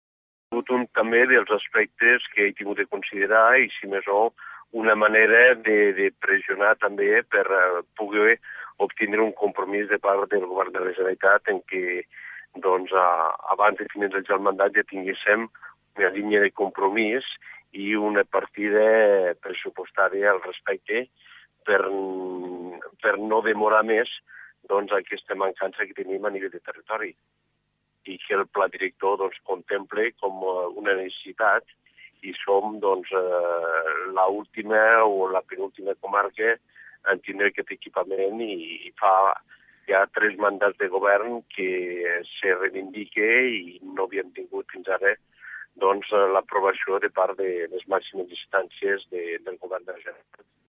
Declaracions i talls de veu
Enric Mir, alcalde de les Borges i candidat de CiU en les pròximes municipals